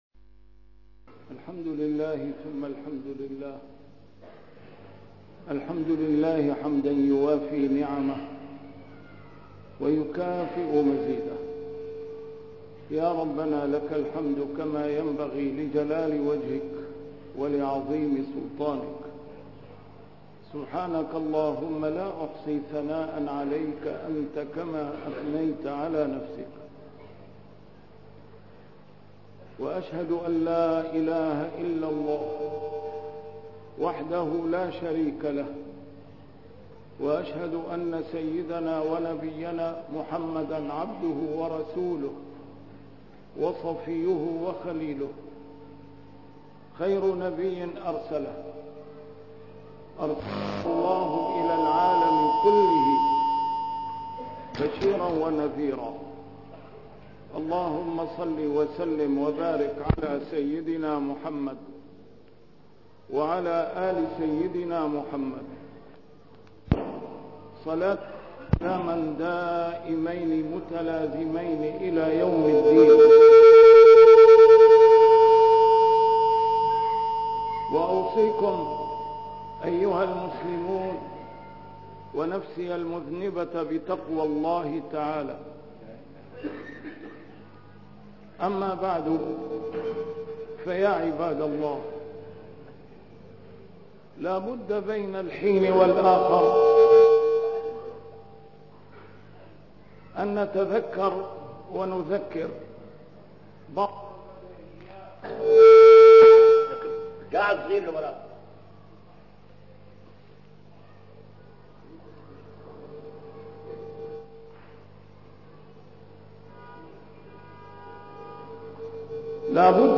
A MARTYR SCHOLAR: IMAM MUHAMMAD SAEED RAMADAN AL-BOUTI - الخطب - لا تسبوا الدهر فإن الله هو الدهر